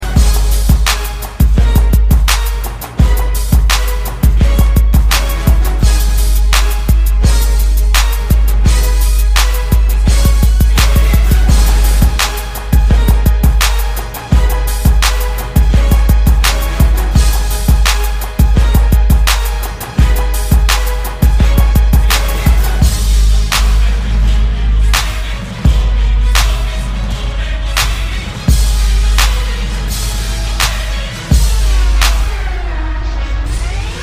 • Качество: 128, Stereo
без слов
Bass
instrumental hip-hop
beats
минус
Самодельная инструменталка песни